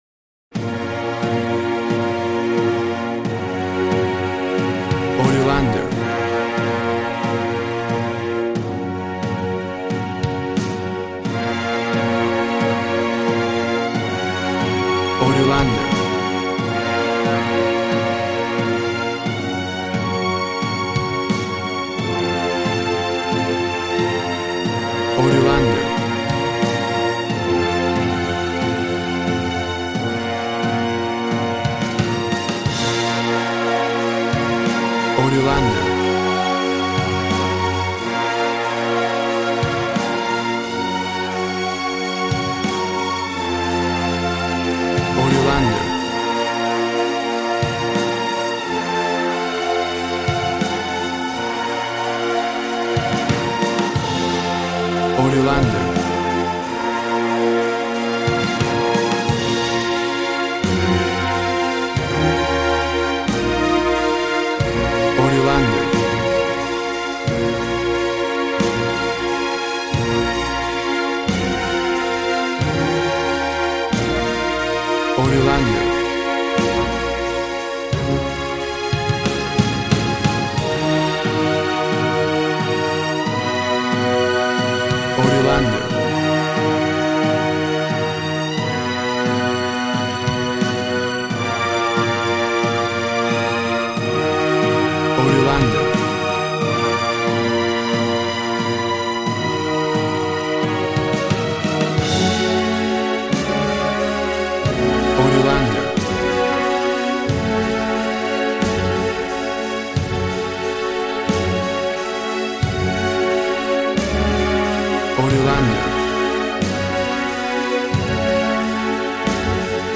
Tempo (BPM) 90